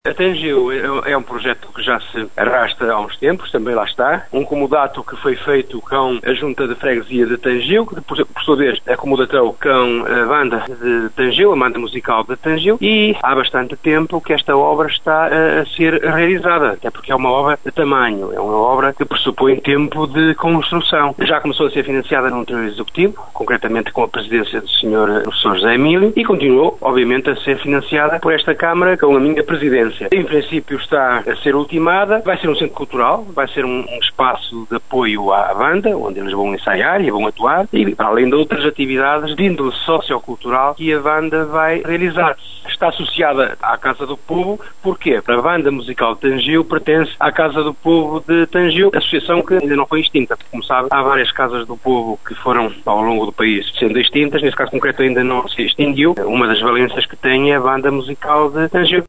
monçao-transformação-escolas-augusto-domingues-2.mp3